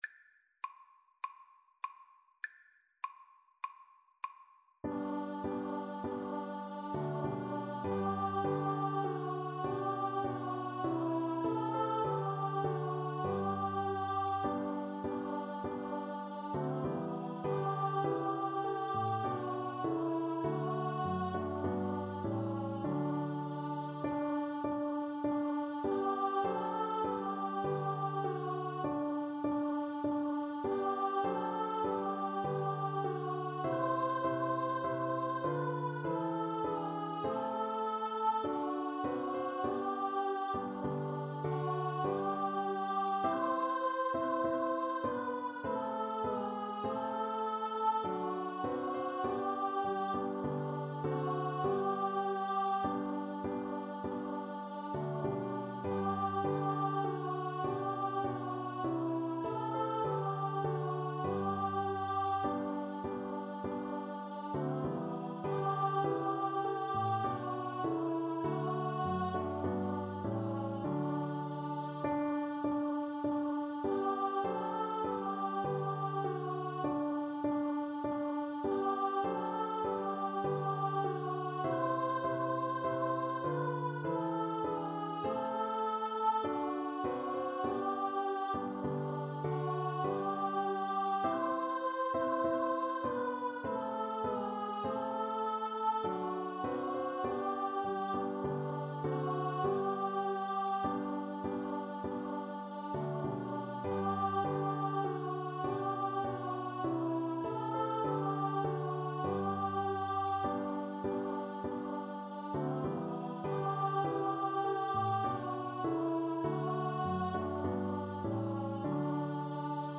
Free Sheet music for Choir (SATB)
G major (Sounding Pitch) (View more G major Music for Choir )
4/4 (View more 4/4 Music)
Traditional (View more Traditional Choir Music)
hark_the_herald_CHOIR_kar2.mp3